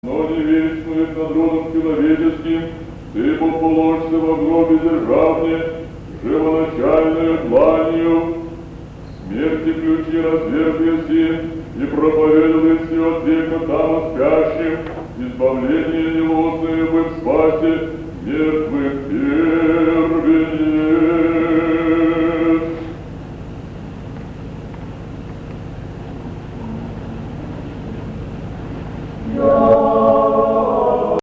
Свято-Троицкий монастырь в Джорданвилле.
08_Lamentations_Canon.mp3